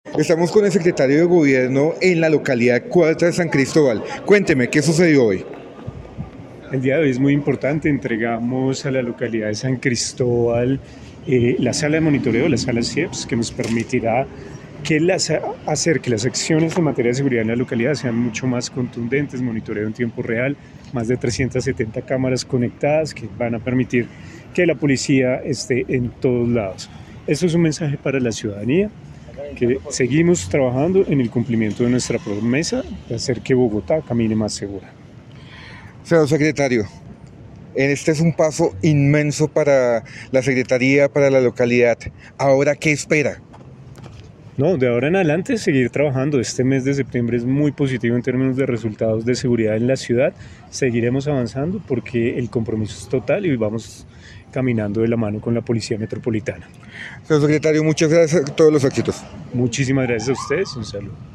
En un avance significativo para la seguridad de la comunidad, la localidad de San Cristóbal celebró hoy la entrega oficial de su Sala de Monitoreo, un proyecto clave para fortalecer la capacidad de respuesta de la Policía.
El anuncio fue hecho por el Secretario de Gobierno  Gustavo Quintero